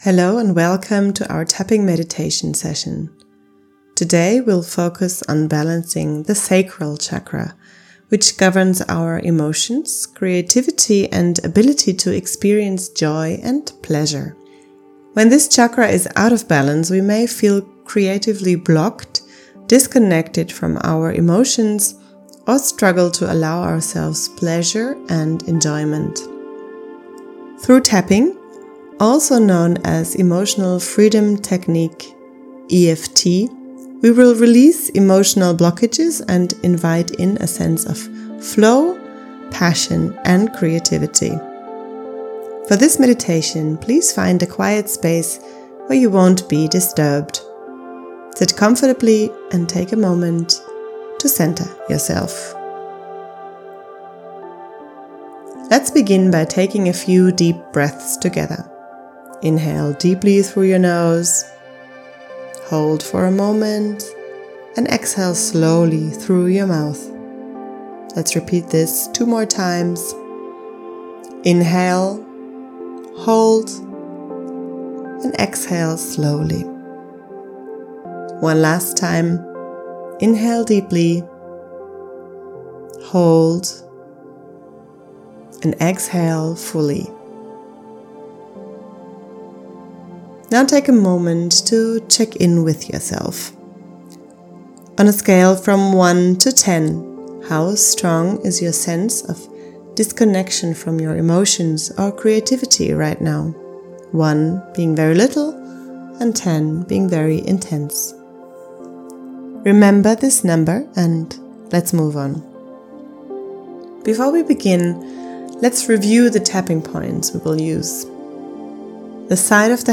Sacral-Tapping-Meditation.mp3